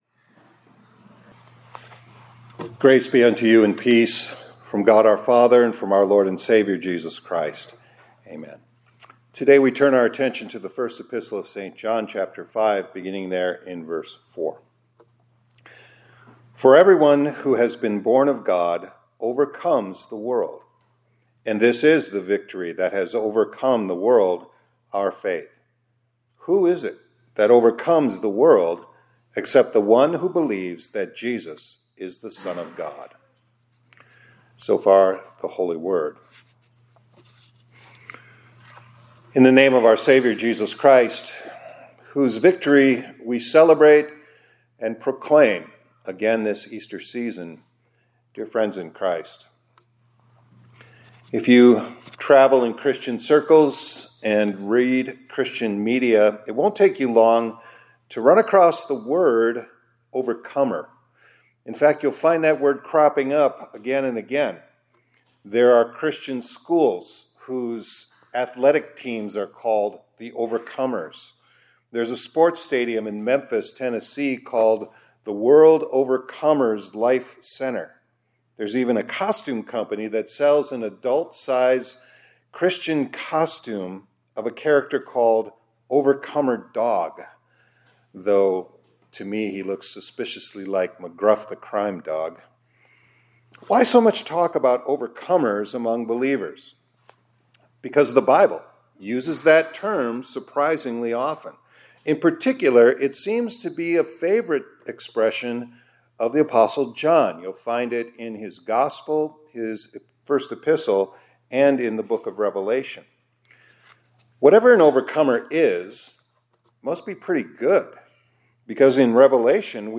2026-04-10 ILC Chapel — Faith Makes You an OVERCOMER